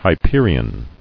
[Hy·pe·ri·on]